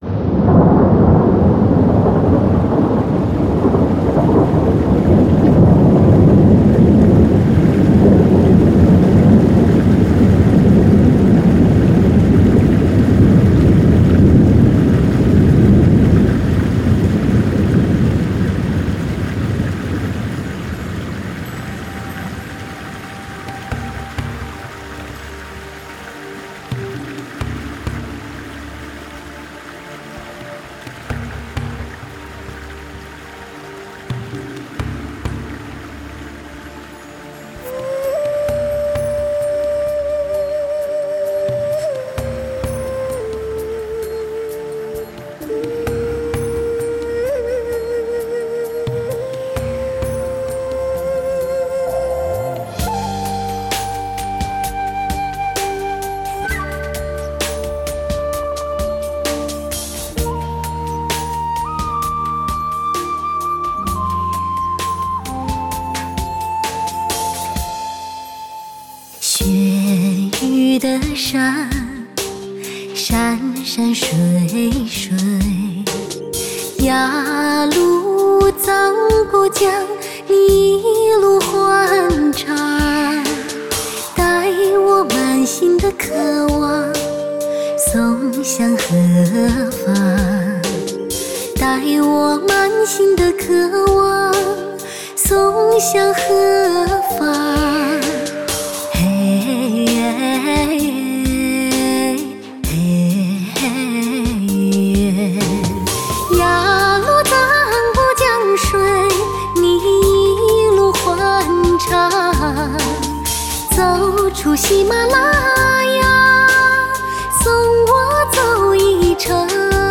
注入诗情画意的东方风情，其韵扬扬悠悠，俨若行云流水。